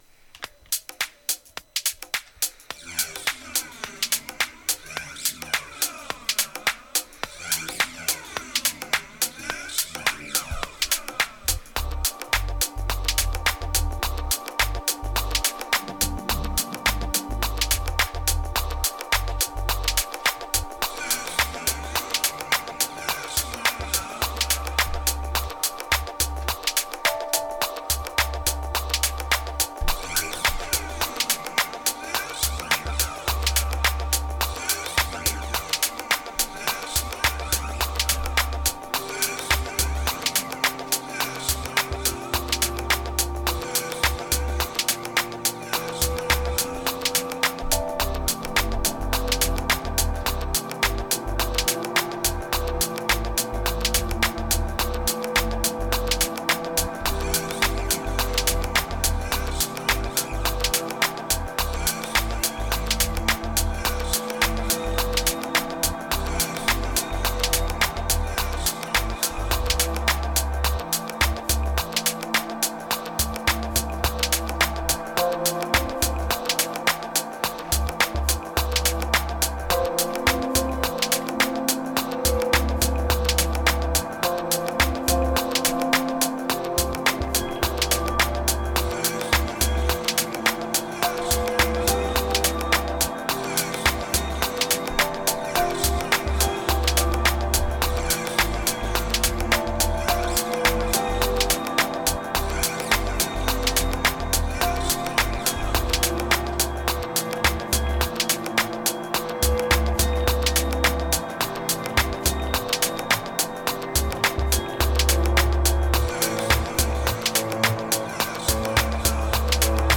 Kicks Epic Riddim Moaner Attic Seldom Healing Tangled Cosmos